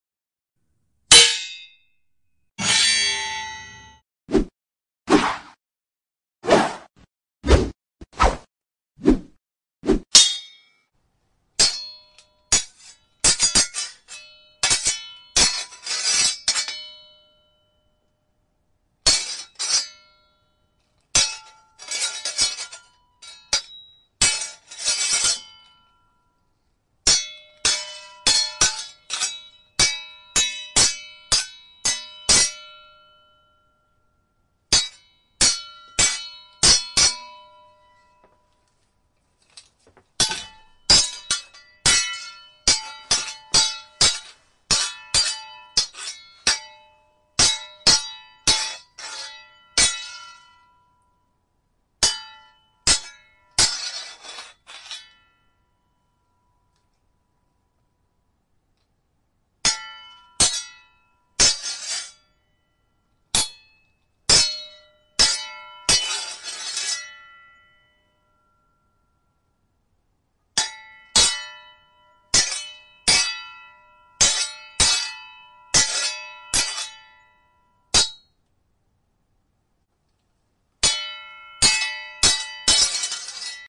Efek Suara Pedang (Beradu, Mencabut, Menyayat, Duel)
Kategori: Suara senjata tempur
Keterangan: Efek suara pedang yang menegangkan untuk adegan beradu, mencabut, menyayat, dan duel, memberikan nuansa dramatis pada video.
efek-suara-pedang-beradu-mencabut-menyayat-duel-id-www_tiengdong_com.mp3